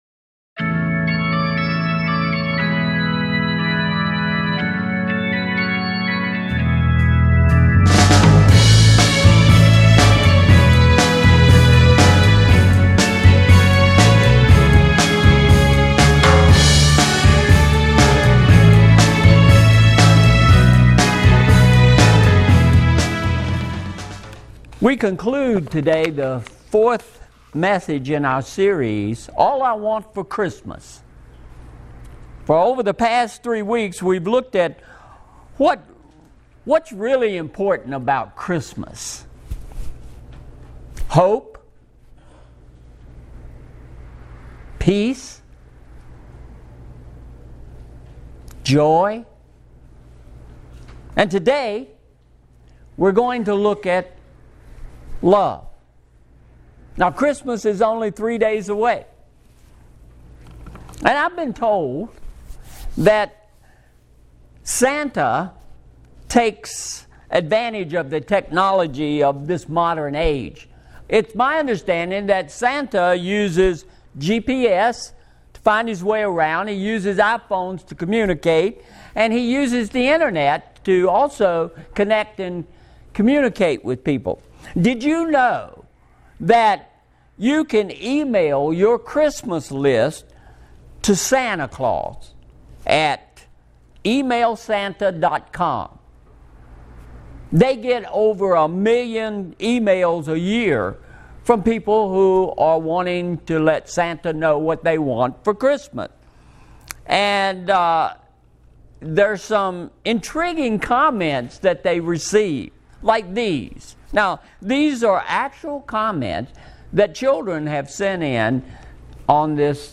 -------------------------- Sermon Synopsis -----------------------------